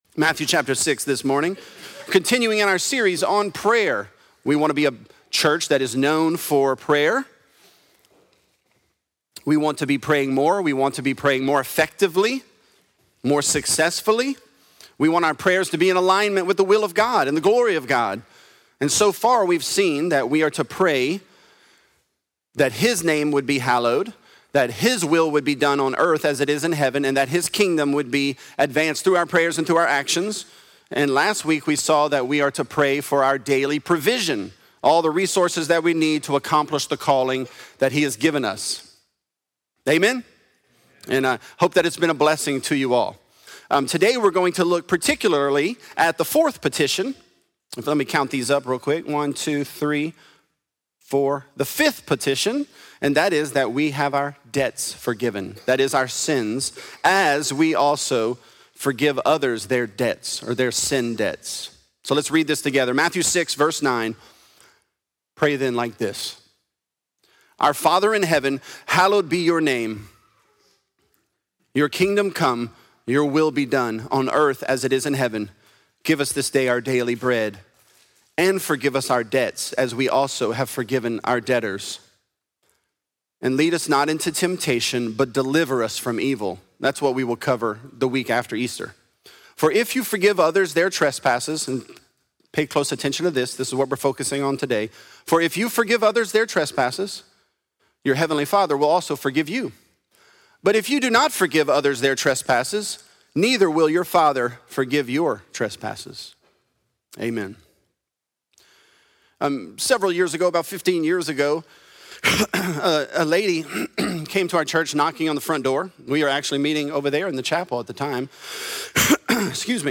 Teach Us To Pray: Forgive Us Our Debts | Lafayette - Sermon (Matthew 6)